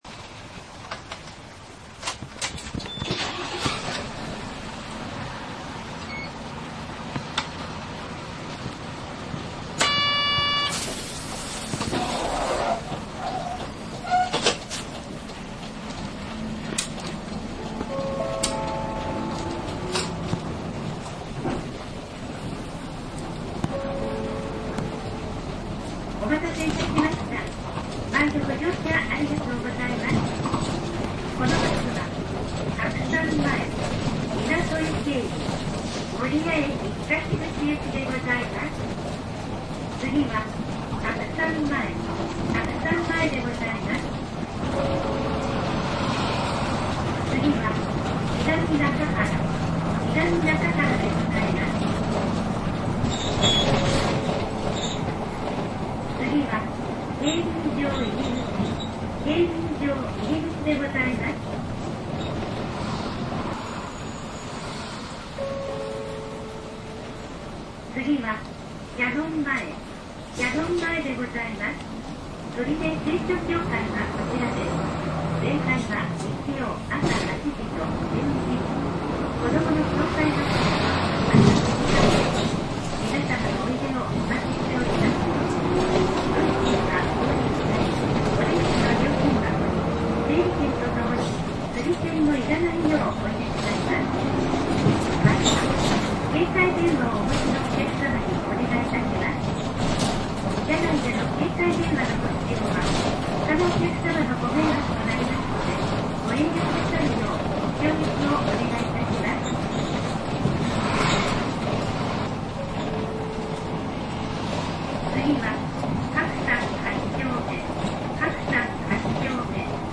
走行音と車内放送で振り返る
車種：左写真のバスで録音しました。
走行音＋全車内放送音